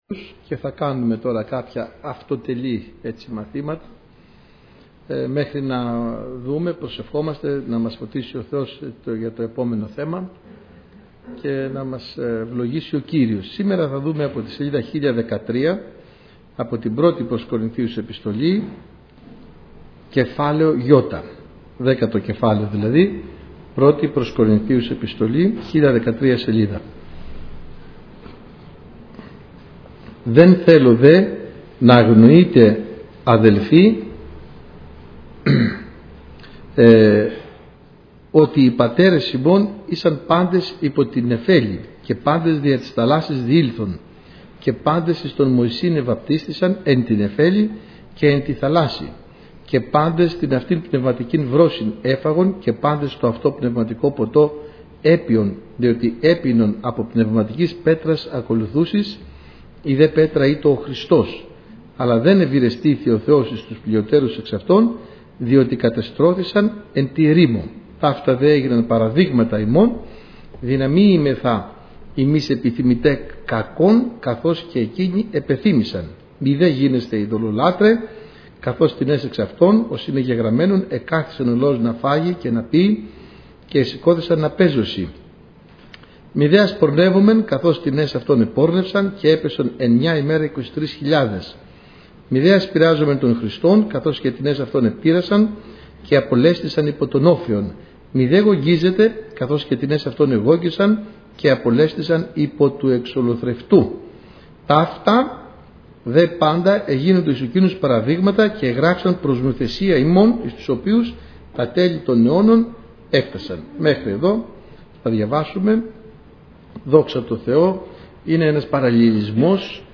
Σειρά: Μαθήματα